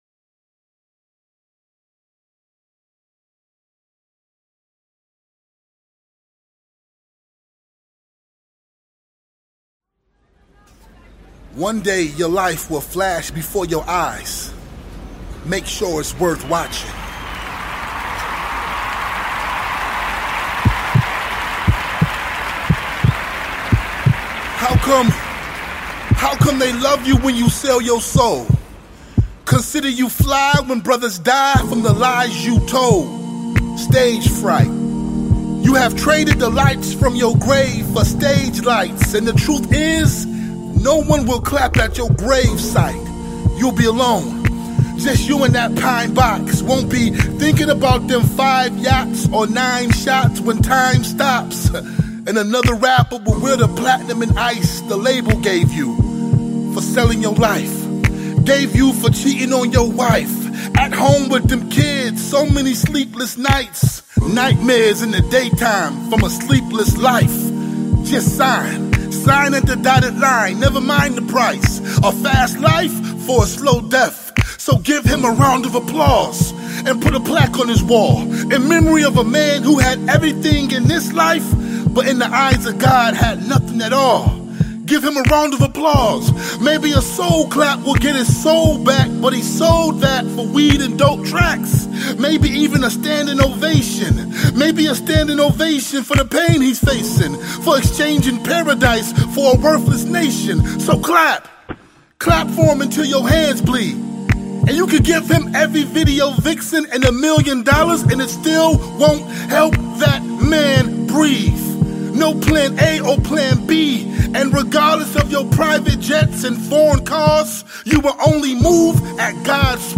All sounds are human voice or percussive.